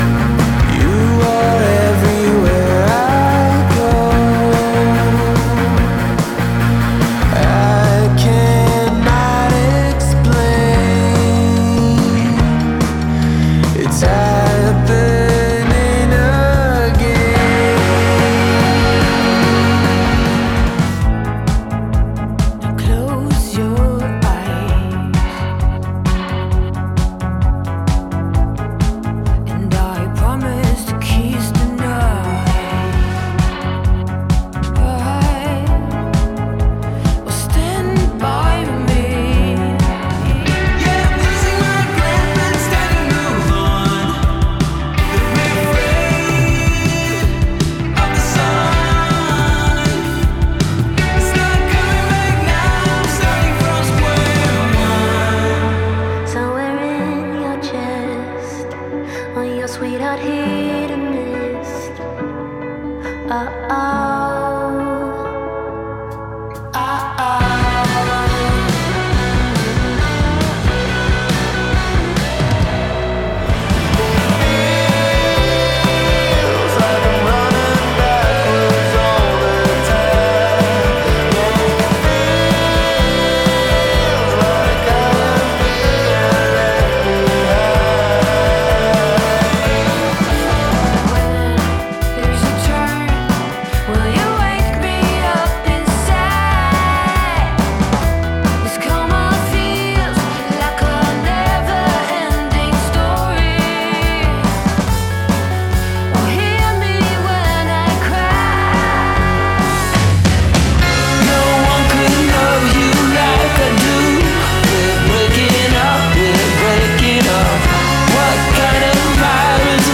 Mid and high-tempo
indie rock, alt,
and classic rock tracks
with bold energy.
INDIE ROCK